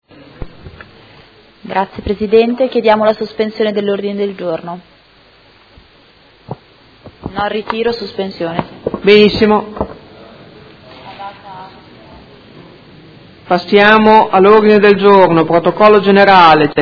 Elisabetta Scardozzi — Sito Audio Consiglio Comunale